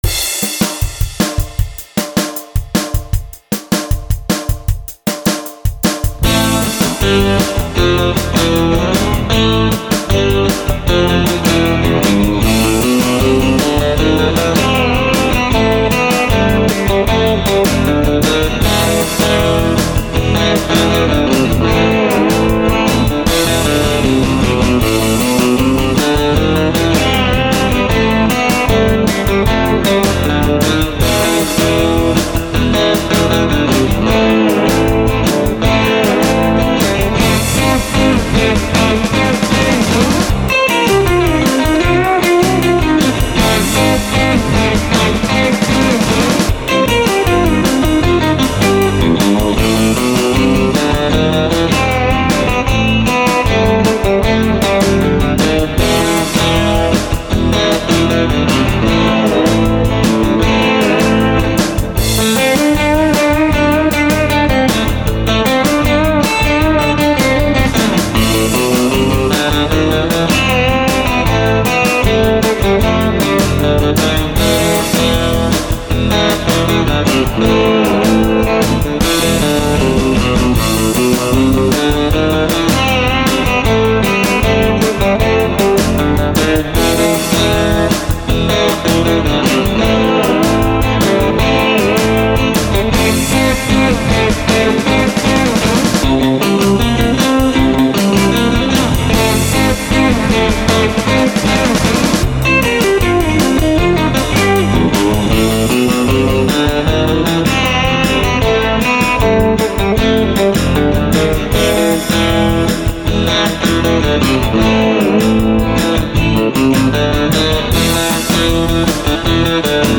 The neck is dark sounding, the bridge is really bright
Here's the bridge pickup in action